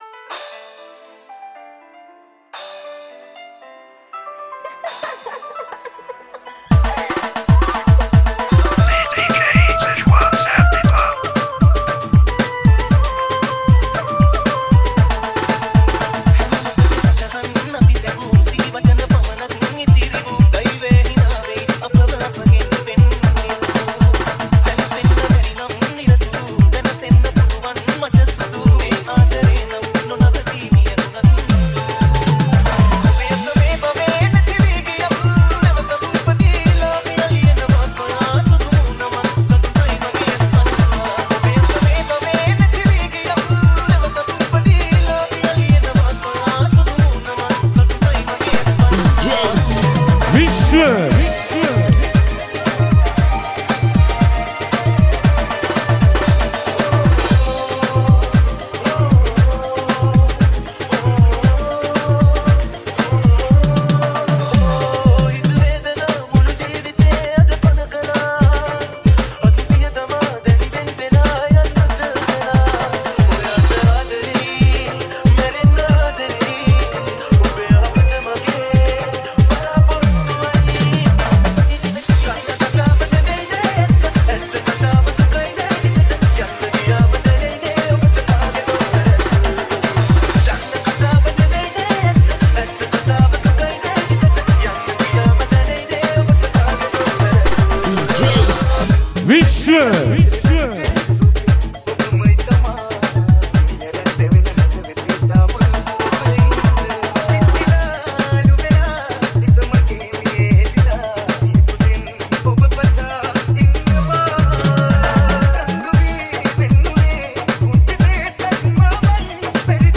Remix
4Minith Song Remix Added 5 Song